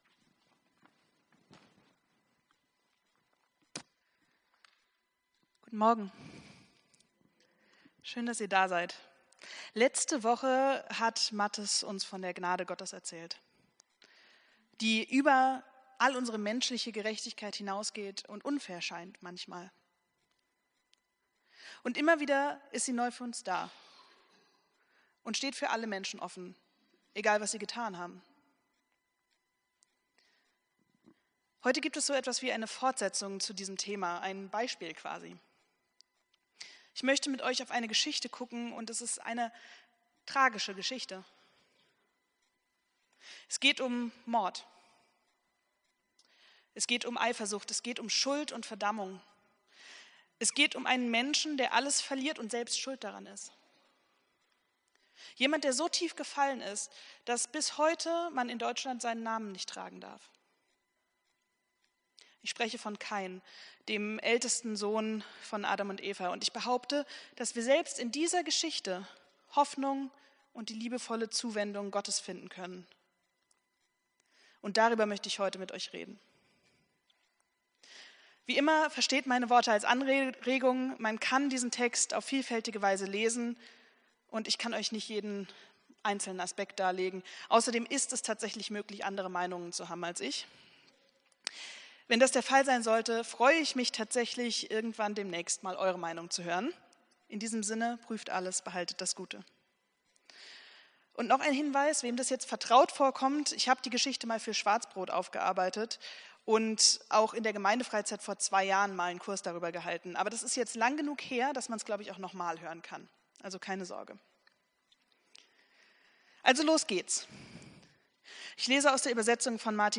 Predigt vom 14.09.2025